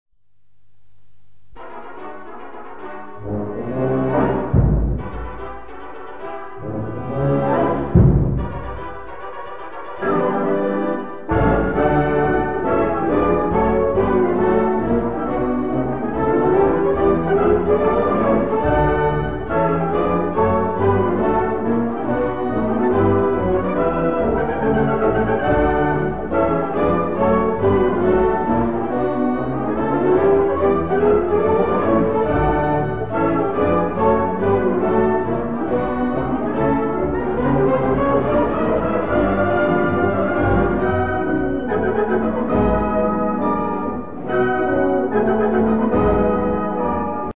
Virtuoser Marsch für Blasorchester